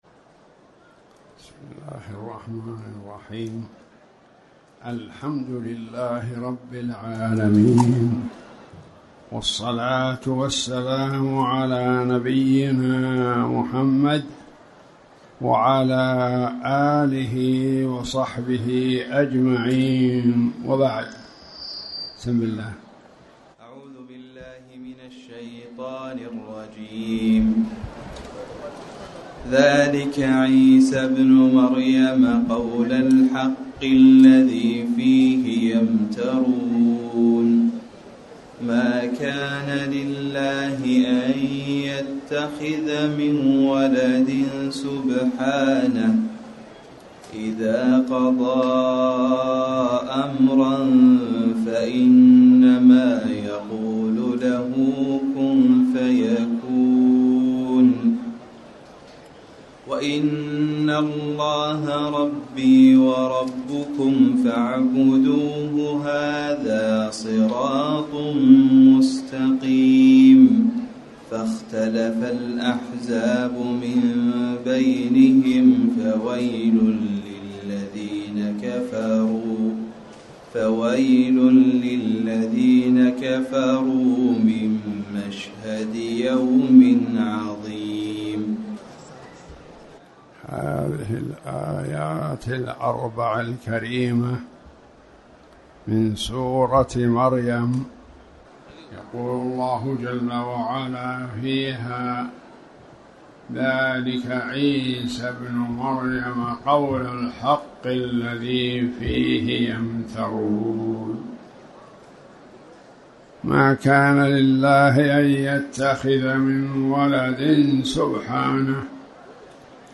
تاريخ النشر ١١ رجب ١٤٣٩ هـ المكان: المسجد الحرام الشيخ